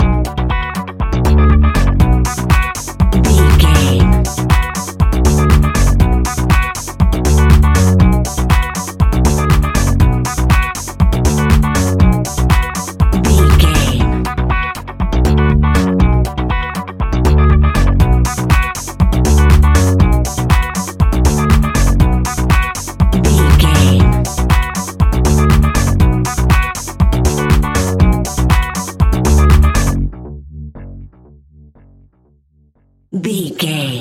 Aeolian/Minor
groovy
futuristic
uplifting
drums
electric guitar
bass guitar
funky house
electro funk
energetic
upbeat
synth leads
Synth Pads
synth bass
drum machines